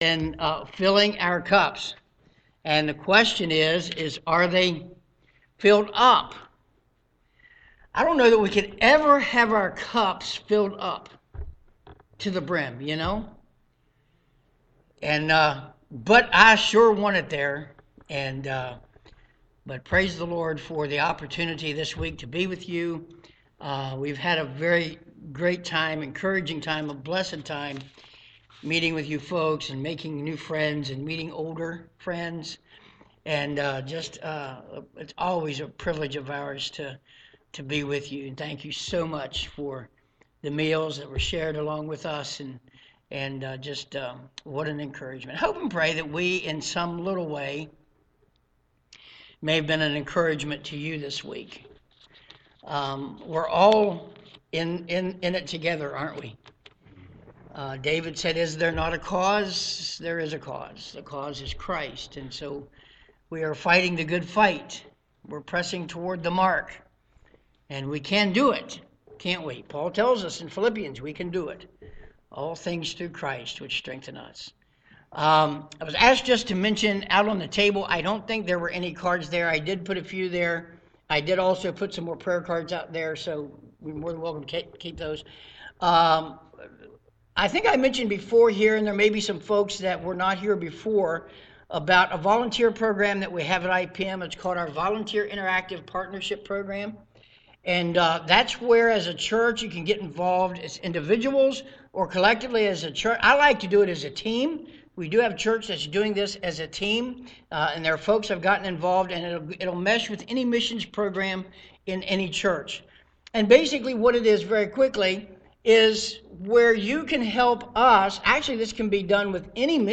2023 Missions Conference
Sermon